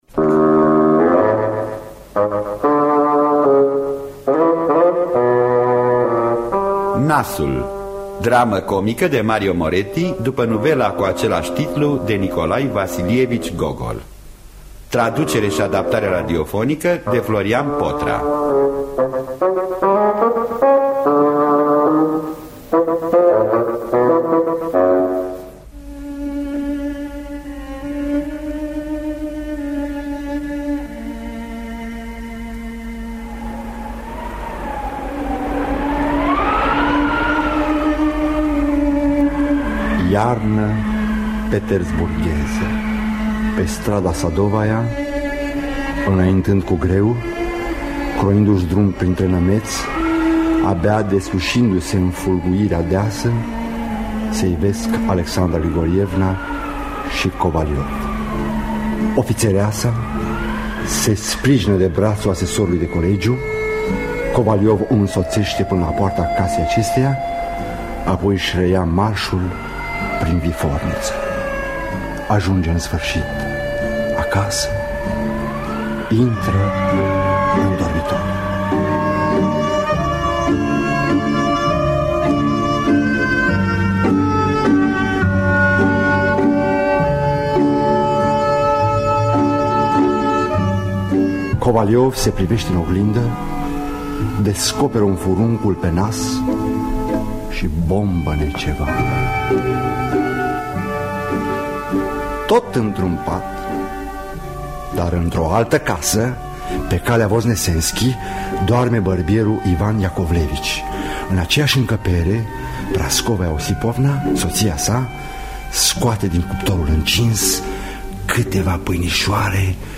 Nașul de Nikolai Vasilievici Gogol – Teatru Radiofonic Online